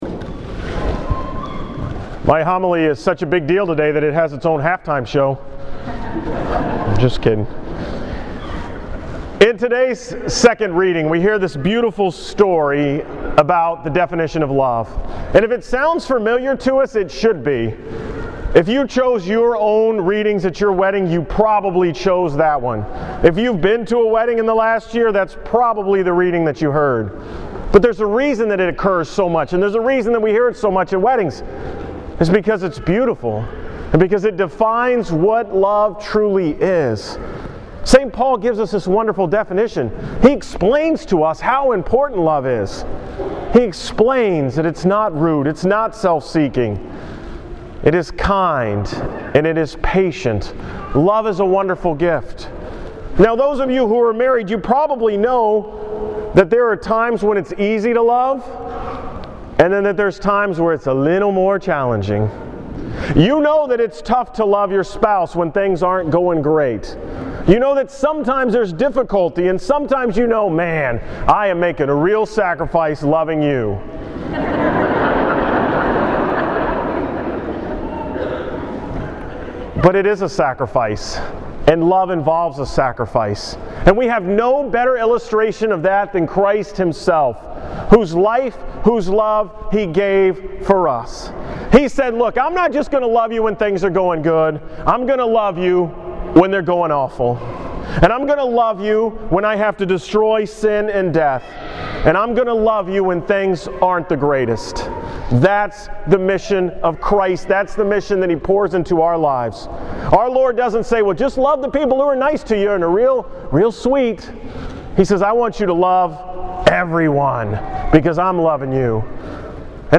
From the 11 am Mass on Sunday, February 3rd.